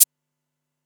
ClosedHH 888 2.wav